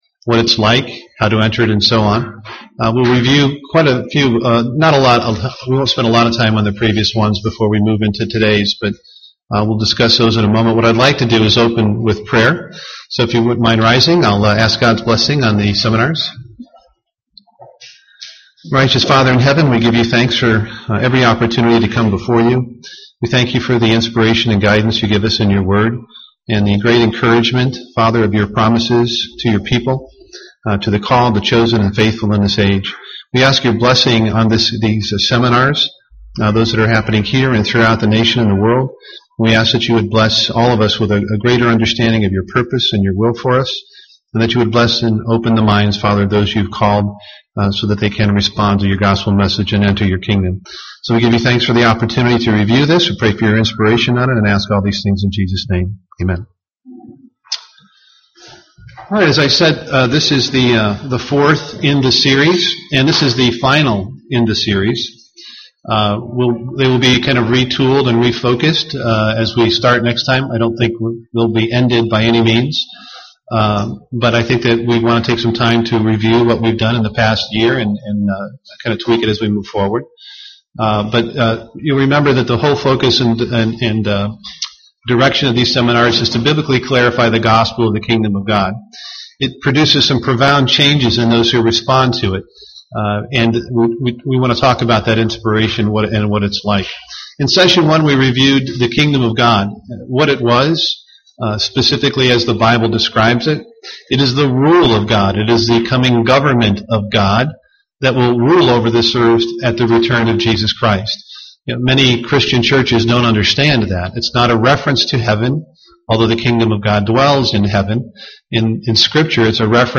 Who are the faithful, and what are the effects of truly believing the Gospel? Learn more in this Kingdom of God seminar.
Given in Twin Cities, MN
UCG Sermon Studying the bible?